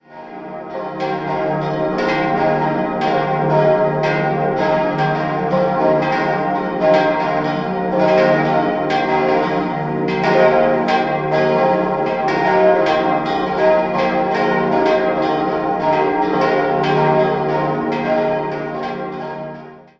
5-stimmiges TeDeum-Geläute: c'-es'-f'-g'-b' Die drei kleineren Glocken wurden 1953 von der Firma Gugg in Straubing gegossen.